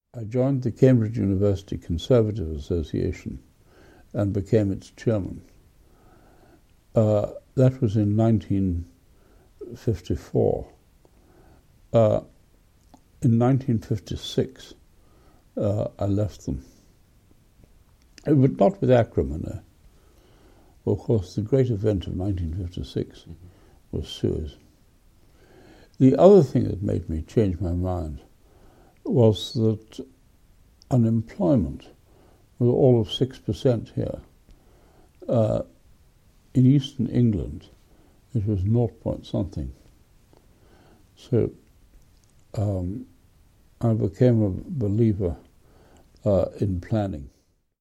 Even in the setting of an oral history interview he proved formidable: seizing our interviewer’s notes before starting, he often seemed to be conducting the interview himself!